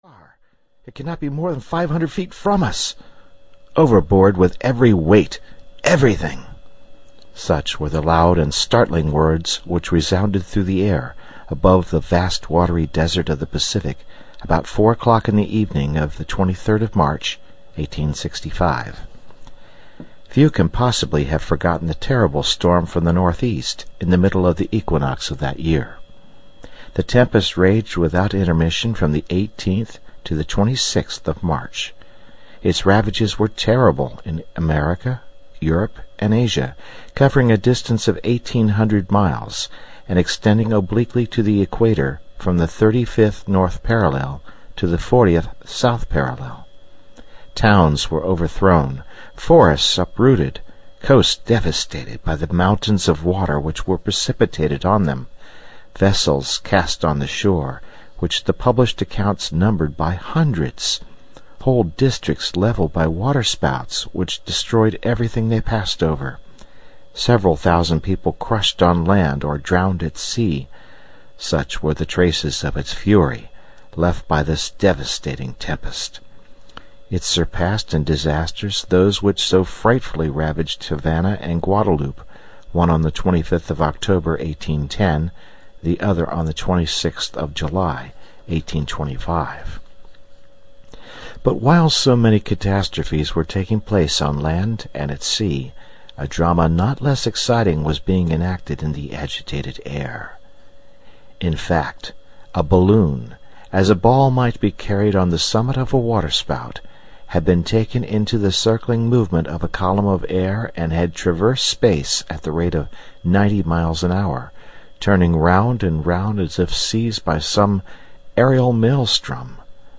The Mysterious Island (EN) audiokniha
Ukázka z knihy